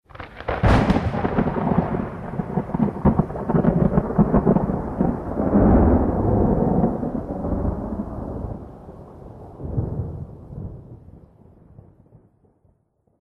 thunder6.mp3